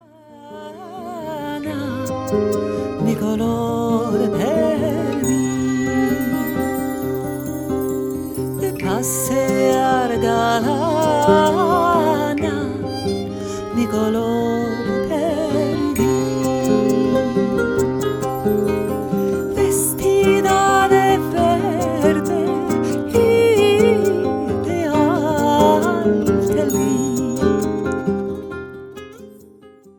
Spanish folk tune